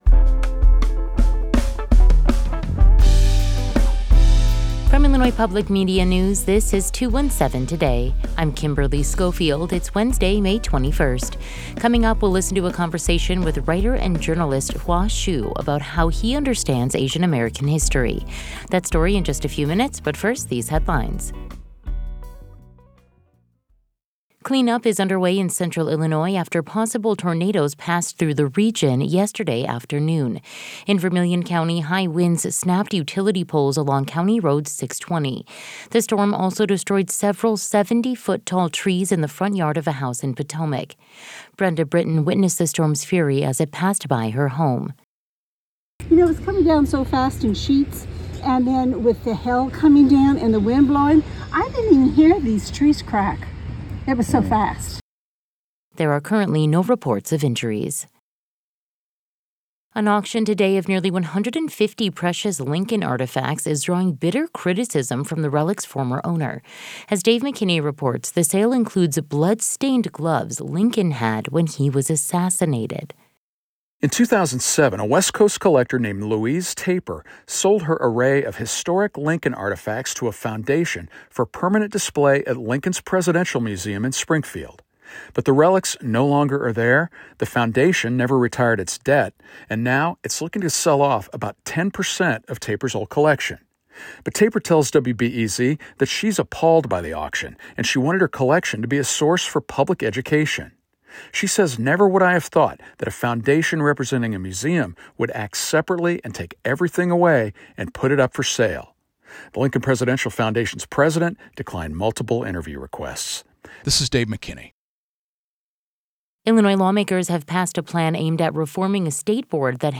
In today's deep dive, we'll listen to a conversation with writer and journalist Hua Hsu about how he understands Asian American history.